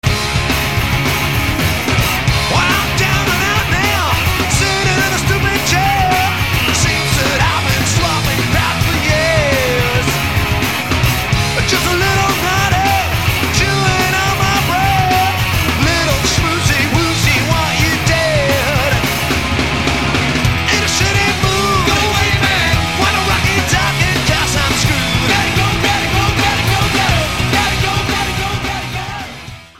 Vuig en smerig rocken zelfs.